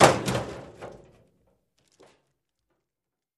Garbage Cans
Garbage Can Impact With Light Plastic Bag Movement